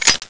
clipout.wav